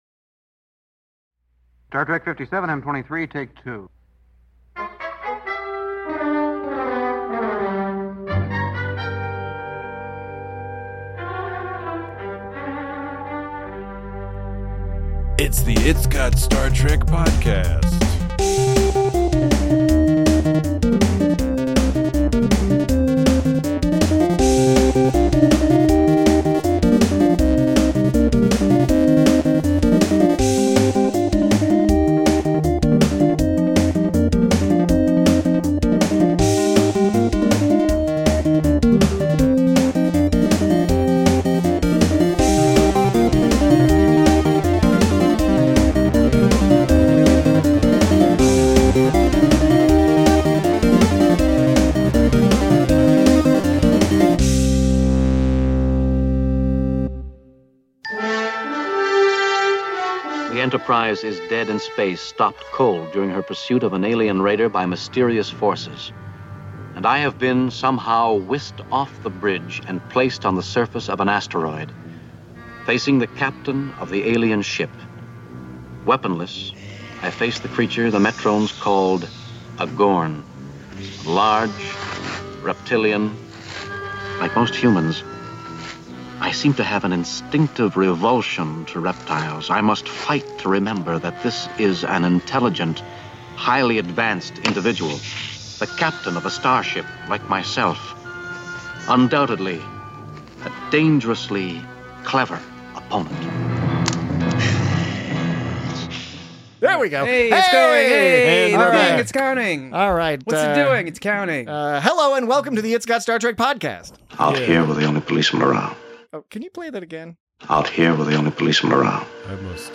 Join your cozy hosts as they discuss various and varied aspects of this classic episode of Star Trek, including production design, pronunciation choices, story structure, and what it was that Kirk and McCoy were alluding to with regard to Commodore Travers' style of hospitality. Also, we are joined by an extremely special guest which makes this a truly not-to-be-missed episode of It's Got Star Trek!